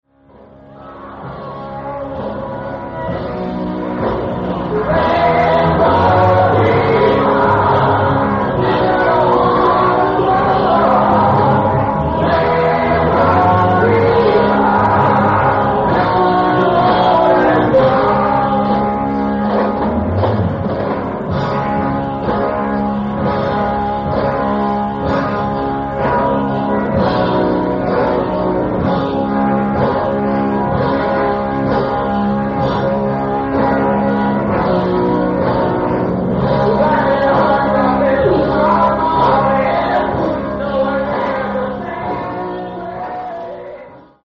Venue:  Rhein-Necker Halle
Source:  Audience Recording